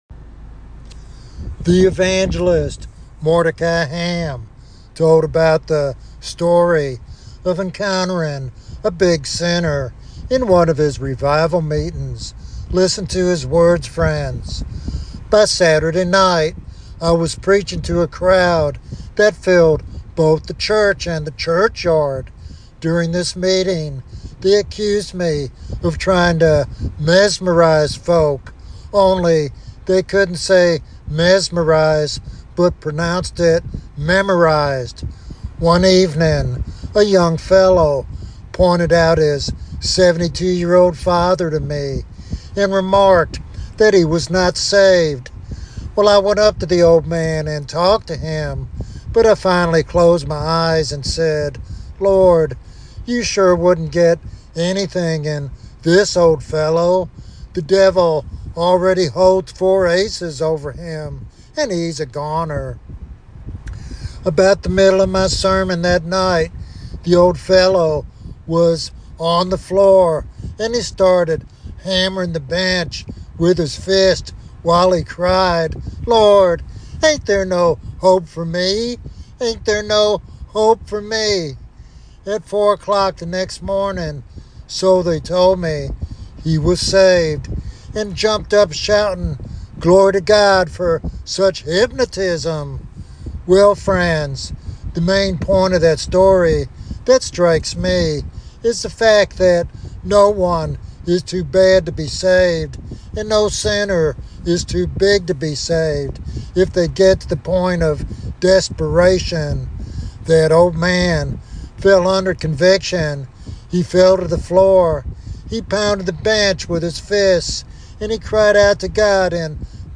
This sermon offers hope that no one is beyond God's mercy and that revival begins with heartfelt repentance.